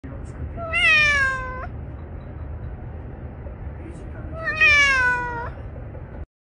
A Cat Meowing
Example of a Cat Meowing
Cat meowing to attract other cats